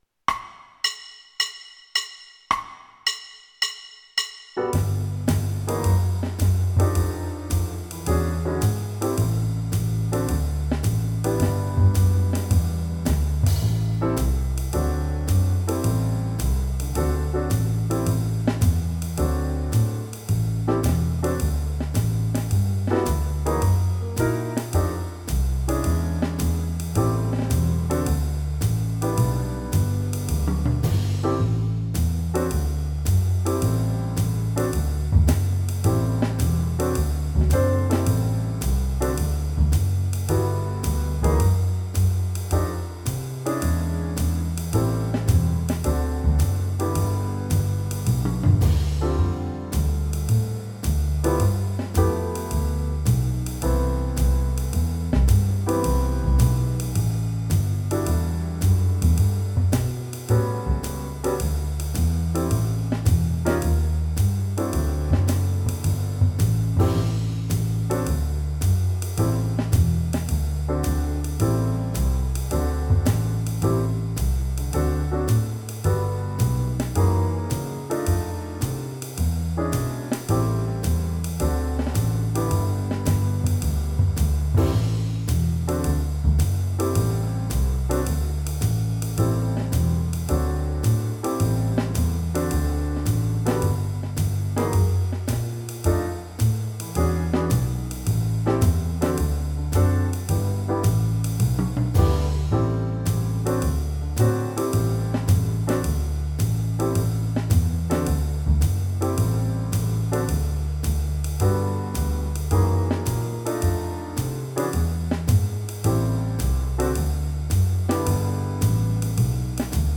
BACKING TRACKS: